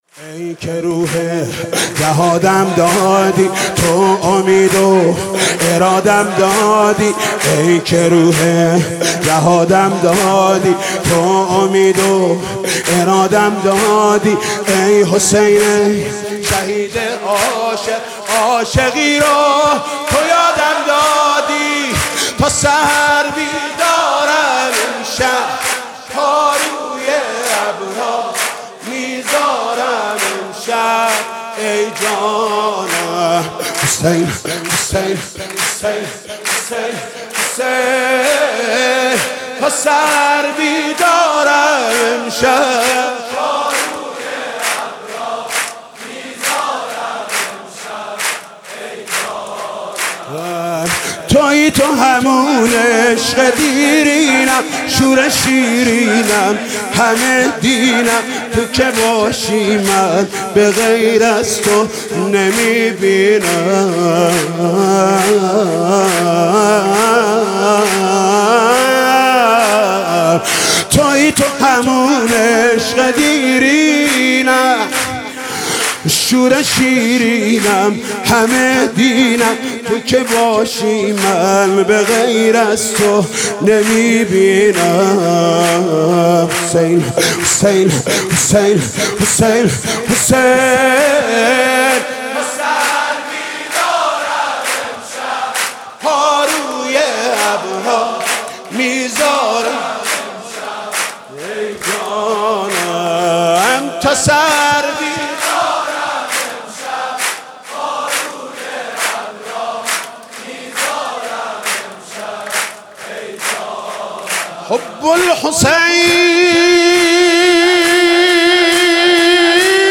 خیمه گاه - عاشقان اهل بیت - ولادت امام حسین(ع)- سرود- ای که روح جهادم دادی- حاج محمود کریمی